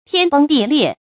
天崩地裂 注音： ㄊㄧㄢ ㄅㄥ ㄉㄧˋ ㄌㄧㄝ ˋ 讀音讀法： 意思解釋： 比喻重大的事變。